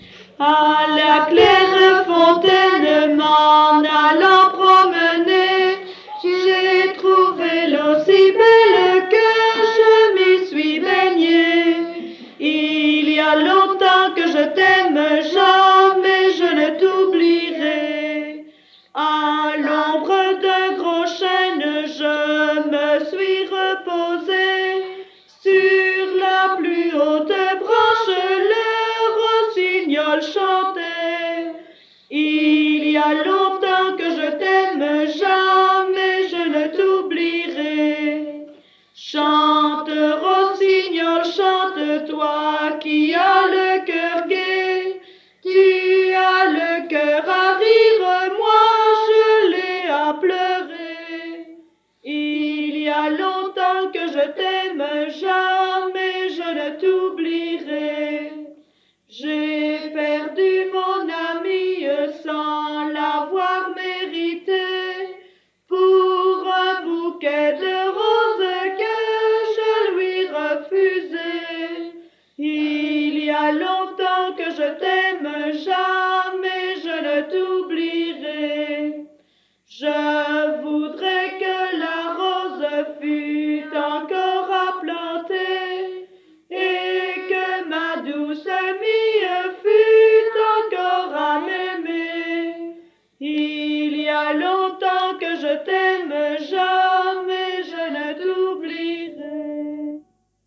Genre : chant
Type : chanson d'enfants
Interprète(s) : Anonyme (femme)
Lieu d'enregistrement : Surice
Support : bande magnétique